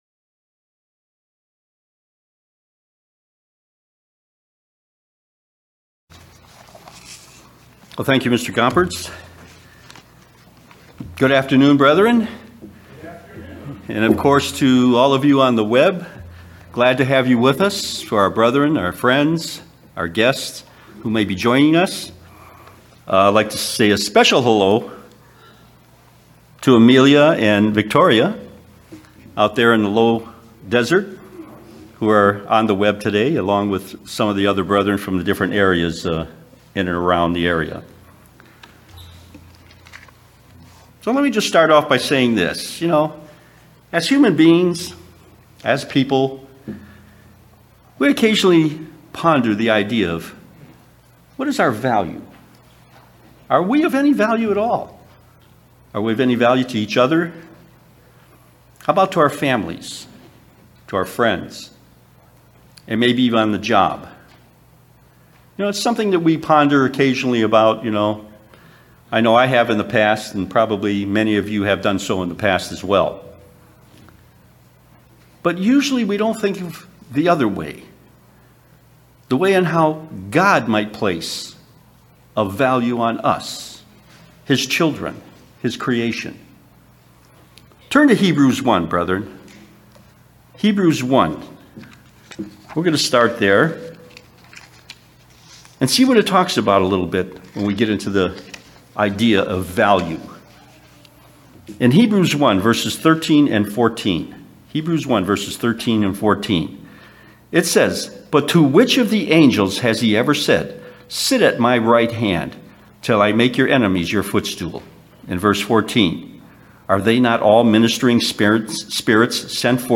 Sermons
Given in Las Vegas, NV Redlands, CA San Diego, CA